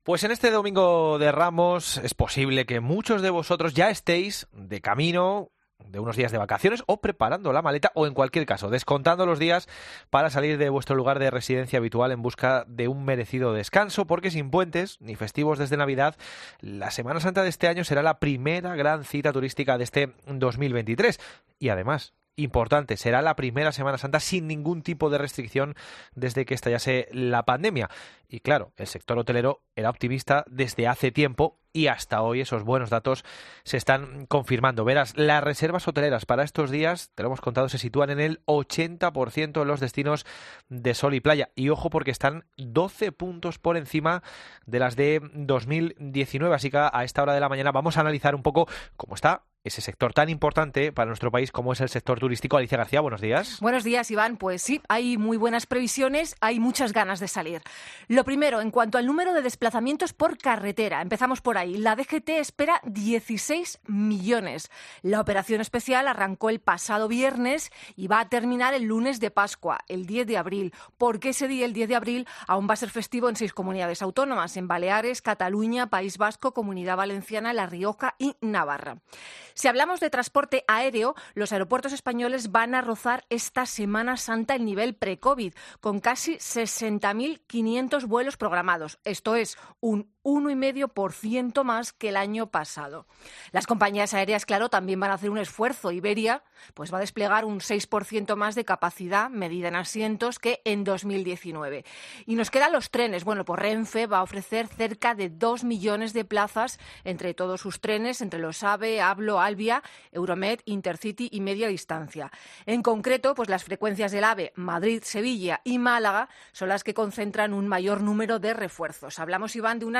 En 'La Mañana Fin de Semana' de COPE nos hemos desplazado a diferentes puntos de España para analizar cómo va a ser el turismo esta Semana Santa.